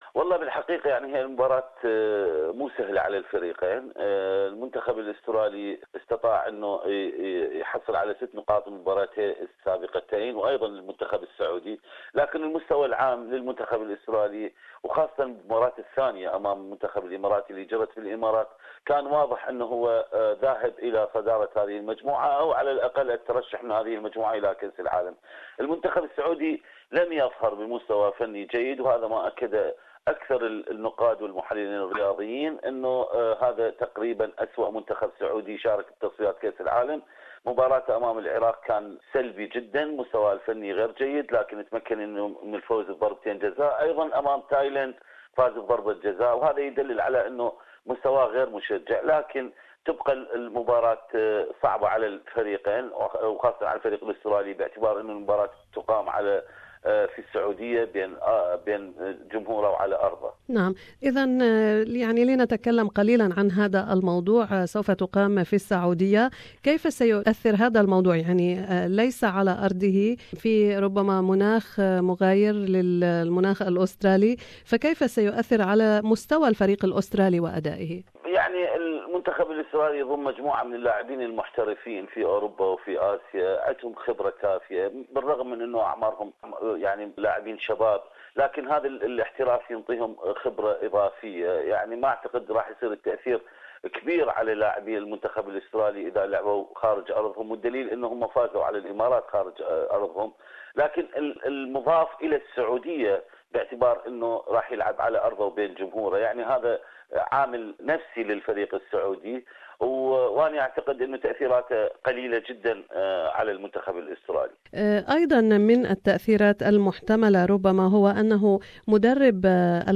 The Socceroos are meeting the Saudi team on the 6th of October in Jedda. An interview with sport analysit